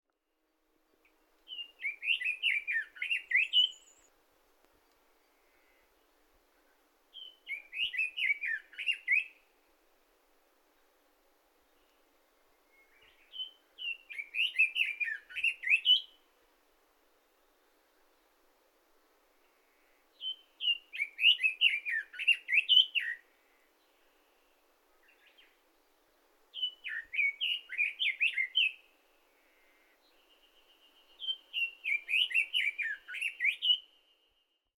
鳴き声はガビチョウに似ているが、ソウシチョウの鳴き声は小鳥らしい細い声のような感じがする。
【録音③】 ソウシチョウ